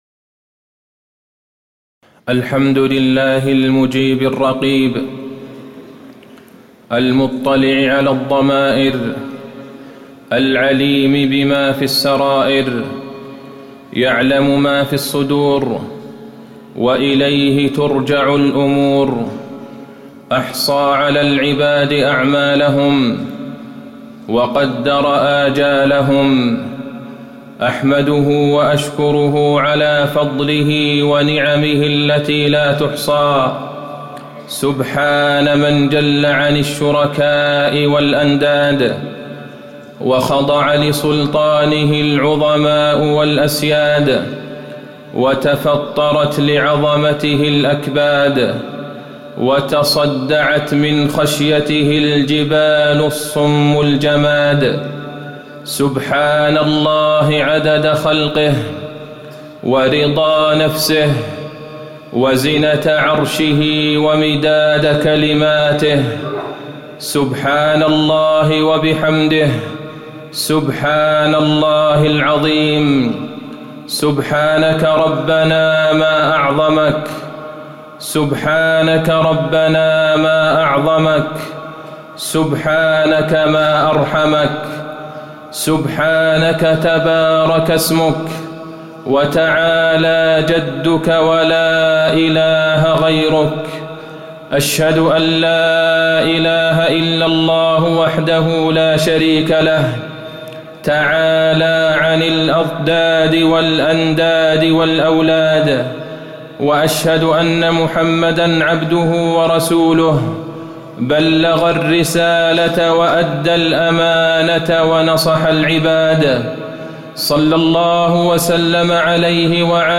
تاريخ النشر ٢٠ جمادى الأولى ١٤٣٨ هـ المكان: المسجد النبوي الشيخ: فضيلة الشيخ د. عبدالله بن عبدالرحمن البعيجان فضيلة الشيخ د. عبدالله بن عبدالرحمن البعيجان خشية الله في الغيب